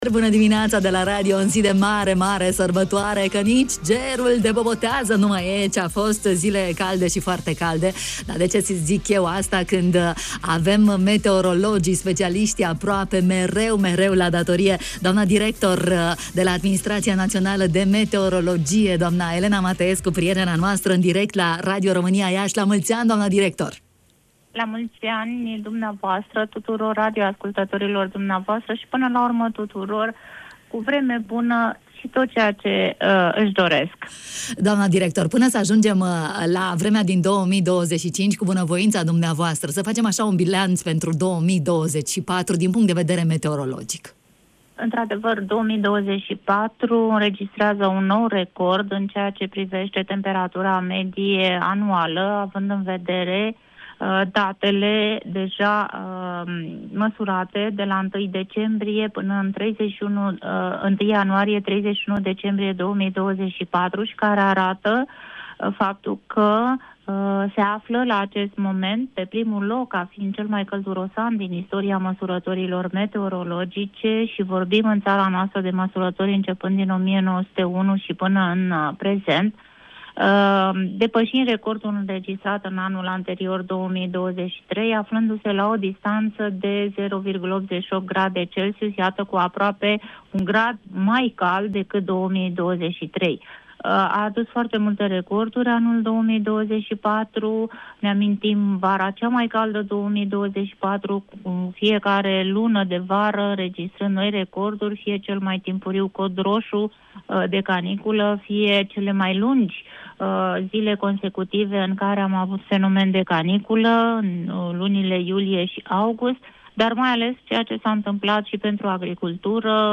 Șefa Administrației Naționale de Meteorologie (ANM), Elena Mateescu, ne-a adus toate detaliile în matinal.